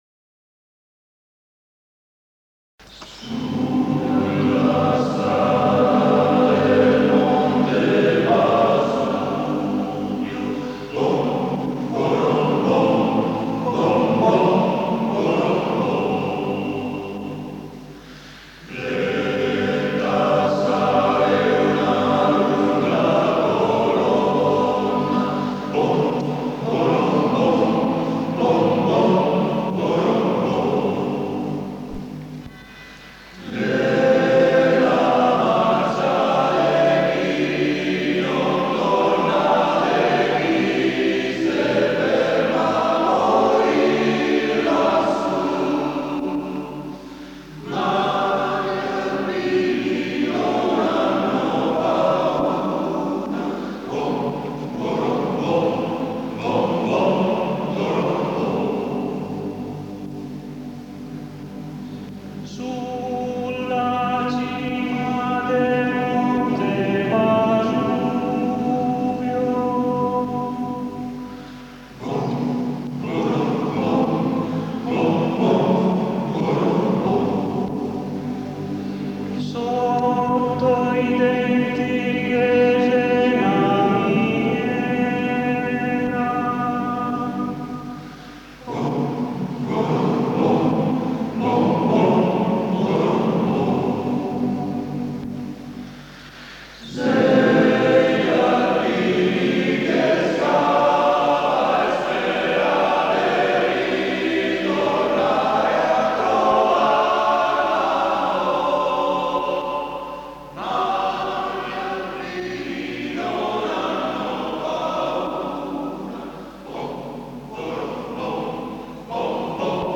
CORO SMALP-103
Esattamente 30 anni dopo la sua registrazione (settembre 1981), è stata ritrovata la cassetta con i canti del nostro coro.